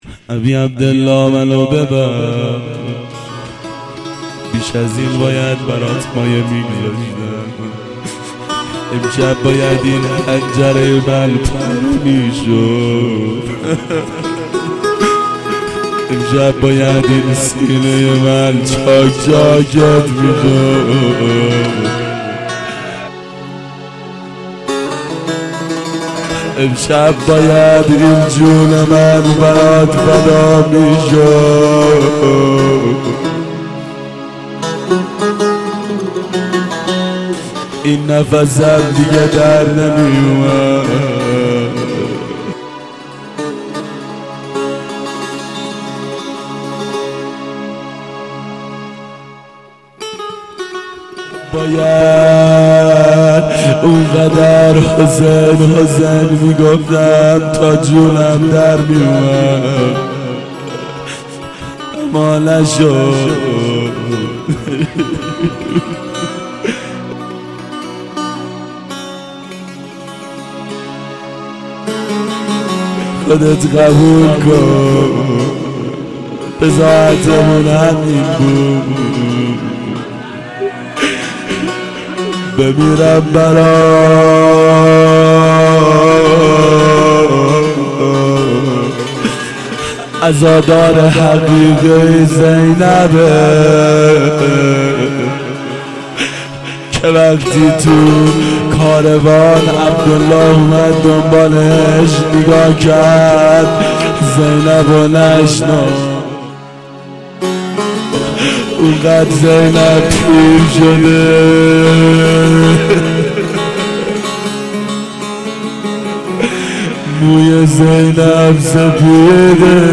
مناجات شب عاشورا
شب عاشورا 1390 هیئت عاشقان اباالفضل علیه السلام